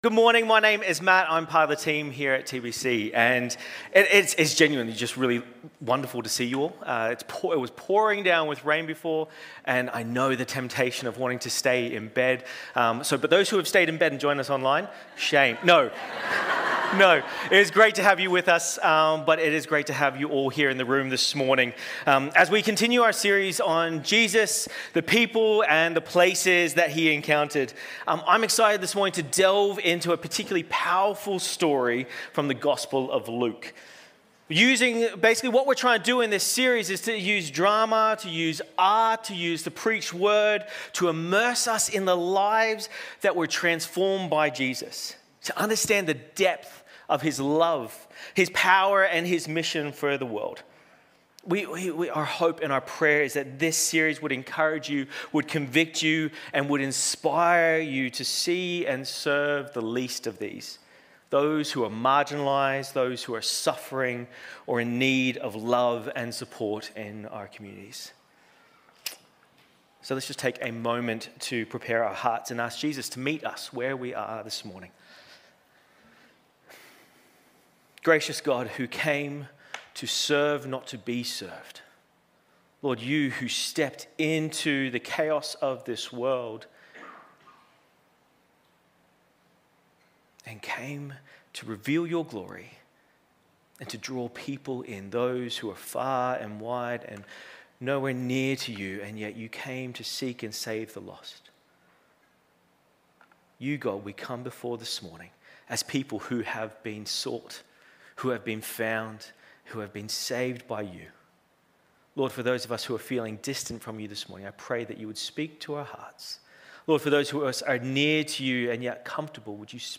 Sermons | Titirangi Baptist Church
As we bring these stories to life through the synergy of preaching and dramatic performance, we pray that it stirs a desire in all of us to extend the life-changing gospel of Jesus to those around us, fostering a community rooted in compassion and action. Today we are looking at Luke 8:26-39 where Jesus encounters a man possessed by a legion of demons.